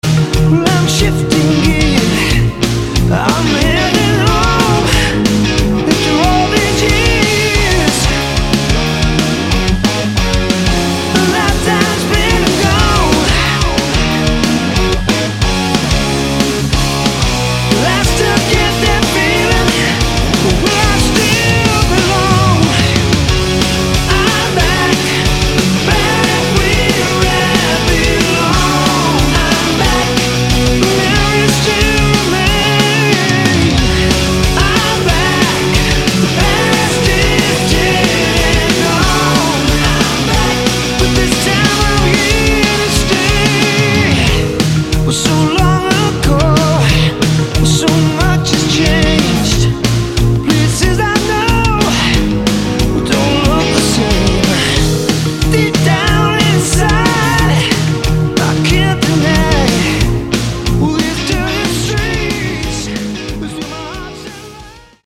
Vocals
Guitars & Keyboards
Drums
Bass